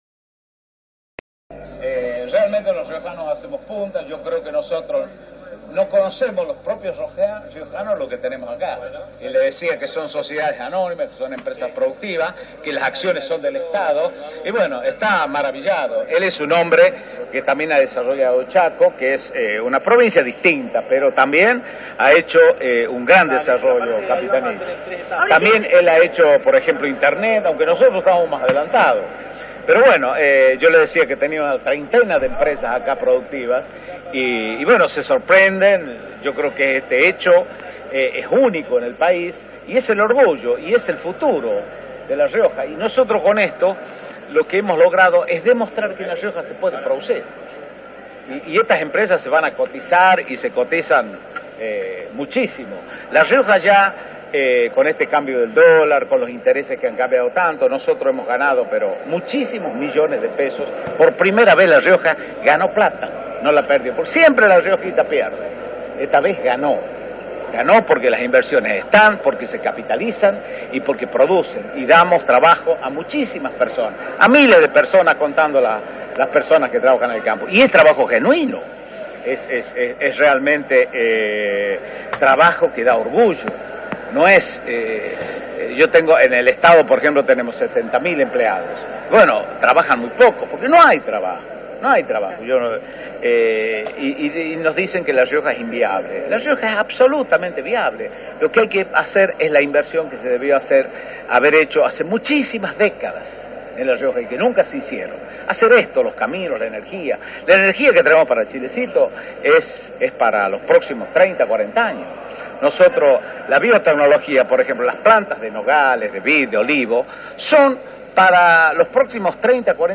Beder Herrera hizo declaraciones en la ciudad de Nonogasta, al visitar la ex Curtiembre Yoma junto al jefe de Gabinete, Jorge Capitanich.